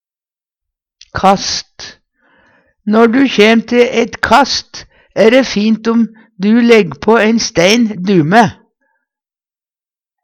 kast - Numedalsmål (en-US)
DIALEKTORD